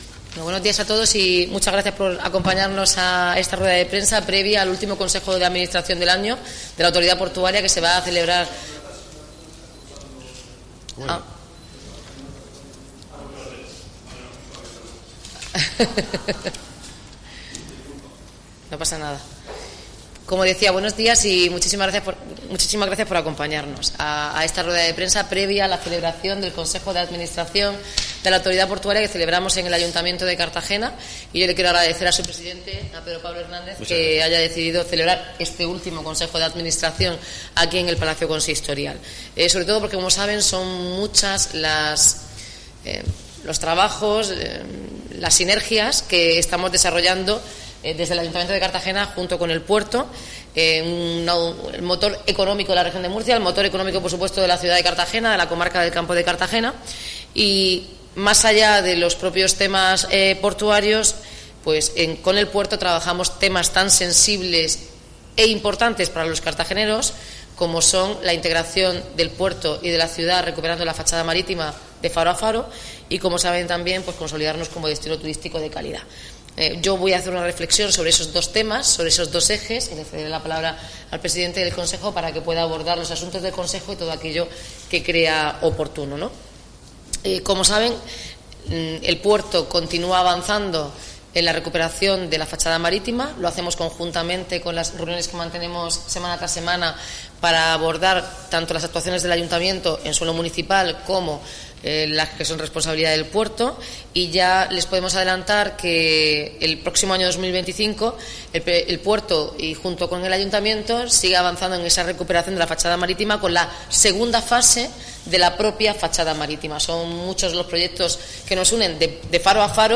Audio: Declaraciones de Noelia Arroyo y Pedro Pablo Hernández (MP3 - 16,49 MB)